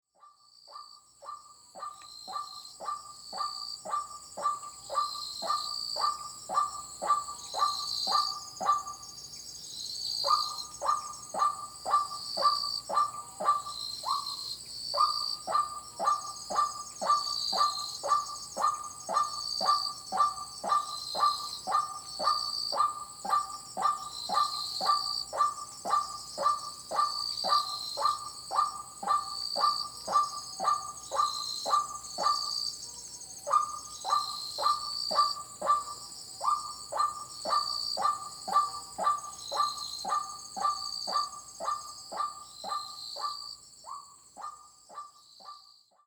This finally became possible in the summer of 2014 and I went there with a four-channel microphone setup.
feat. Northern boobook (Ninox japonica), Ryūkyū scops owl, high pitched sounds ‘maracas-like’ of Eiffinger tree frog, Ryūkyū scops owl, Ryūkyū
kajika frog (Buegeria japonica) and the repetitive calls of White-breasted waterhen.
Field Recording Series by Gruenrekorder